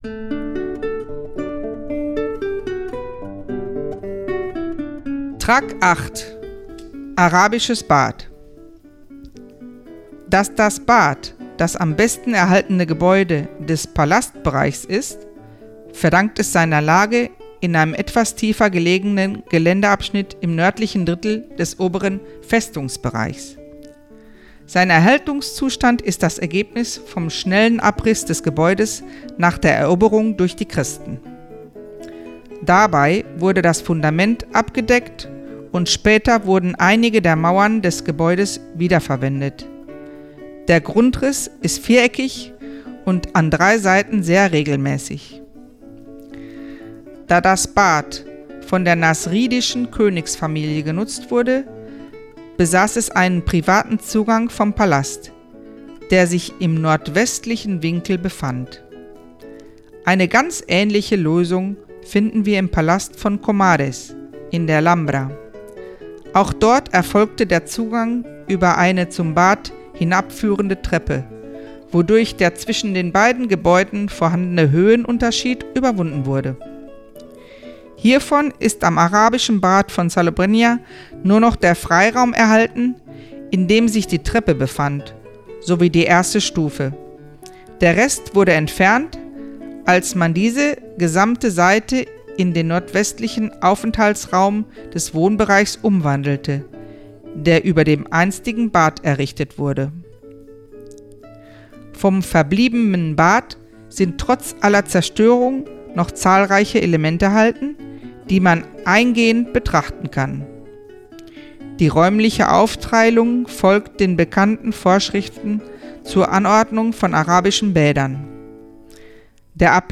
Burg von Salobreña, Besichtigung mit Audioguide